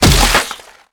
SFX骨断裂音效下载
SFX音效